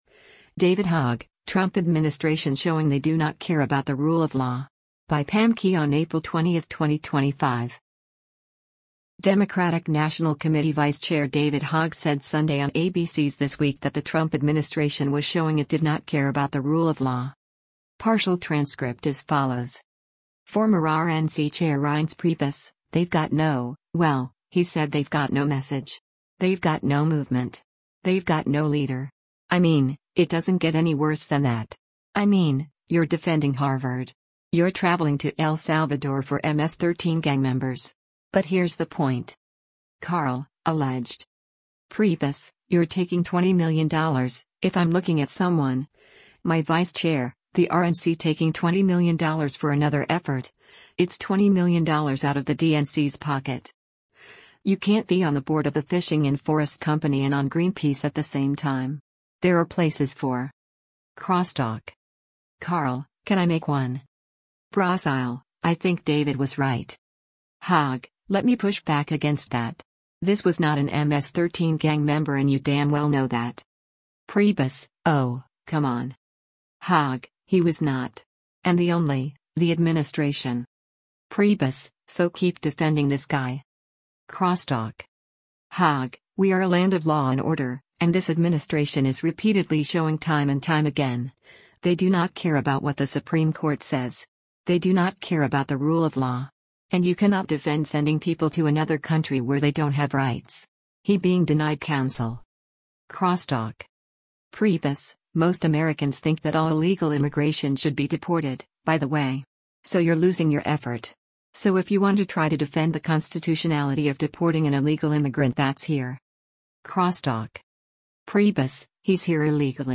Democratic National Committee Vice Chair David Hogg said Sunday on ABC’s “This Week” that the Trump administration was showing it did “not care about the rule of law.”